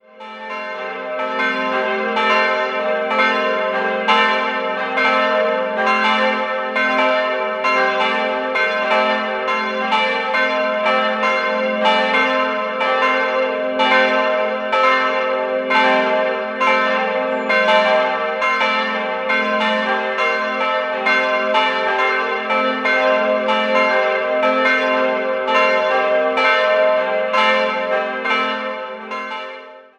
Der Innenraum mit seinen drei Altären besitzt eine Holzkassettendecke. 3-stimmiges Gloria-Geläute: g'-a'-c'' Die Glocken wurden im Jahr 1954 von Friedrich Wilhelm Schilling gegossen.